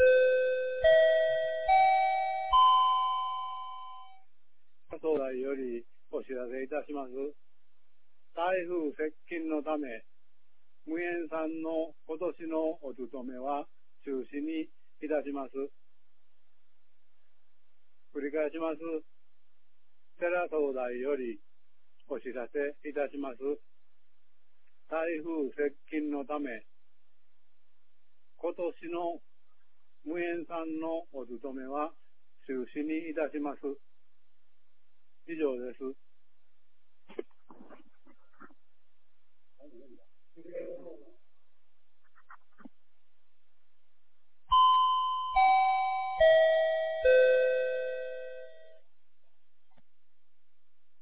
2019年08月14日 13時31分に、由良町から三尾川地区へ放送がありました。